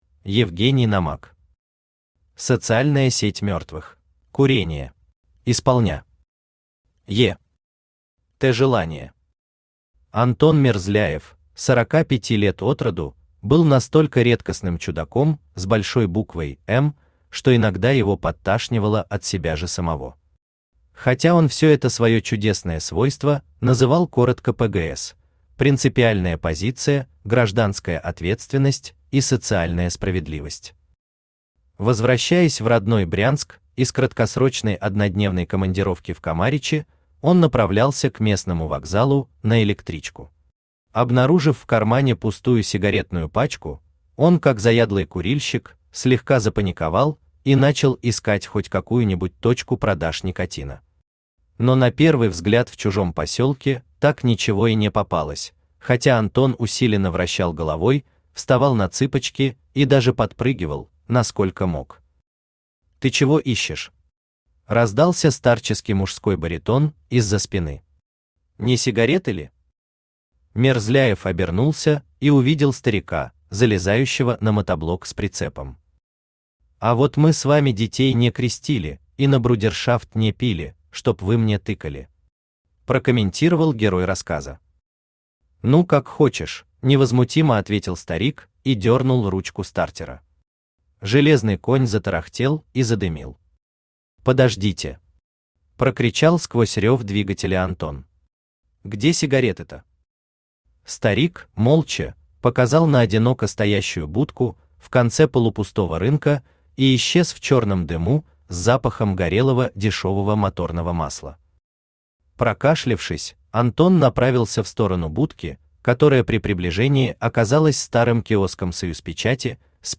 Аудиокнига Социальная сеть мертвых | Библиотека аудиокниг
Aудиокнига Социальная сеть мертвых Автор Евгений Номак Читает аудиокнигу Авточтец ЛитРес.